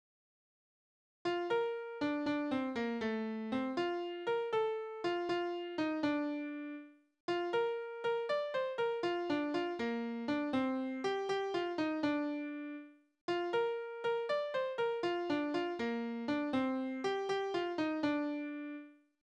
Tonart: B-Dur Taktart: 6/8 Tonumfang: Undezime Externe Links